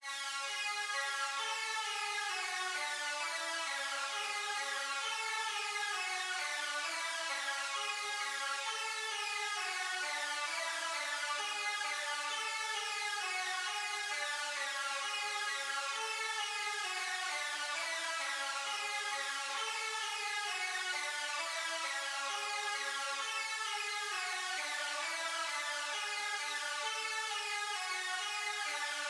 角斗士合成器
描述：使用Gladiator制作的合成器具有Lex Luger的感觉
Tag: 66 bpm Hip Hop Loops Synth Loops 4.90 MB wav Key : Unknown